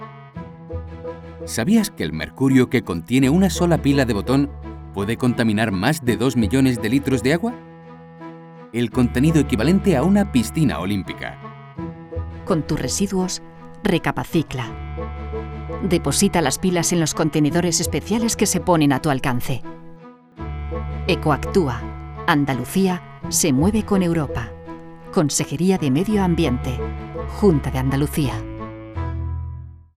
Cuña de Radio Reciclaje de Pilas